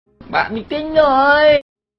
Created by: Độ Mixi nói
Thể loại: Câu nói Viral Việt Nam